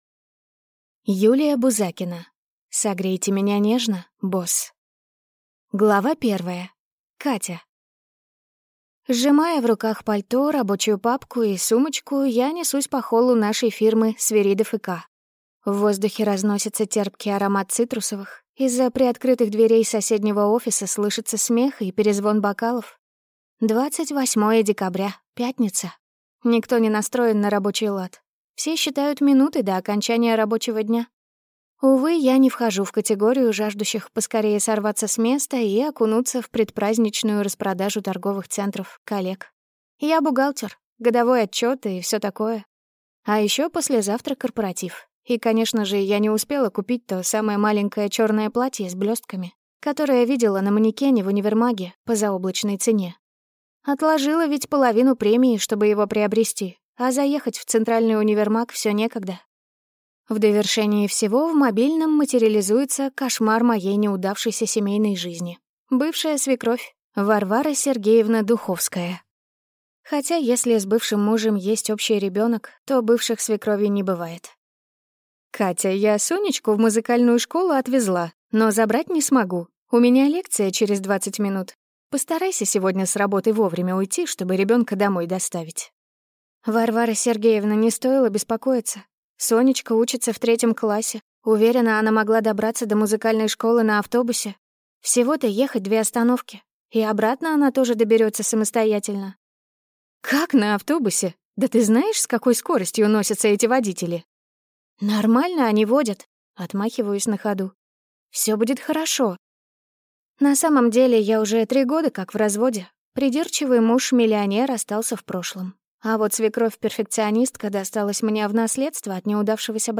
Аудиокнига Согрейте меня нежно, босс!